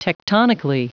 Prononciation du mot tectonically en anglais (fichier audio)
Prononciation du mot : tectonically